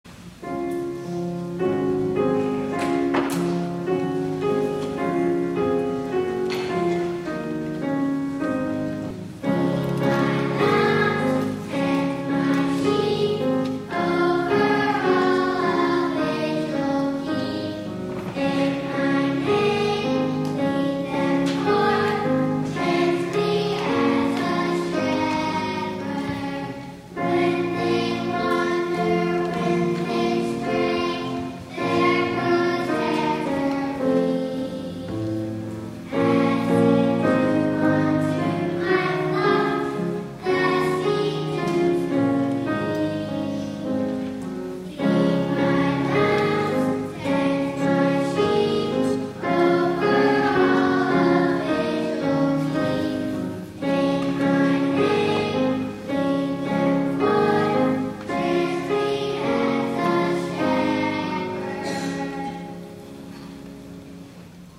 2ND SUNDAY OF LENT
THE INTROIT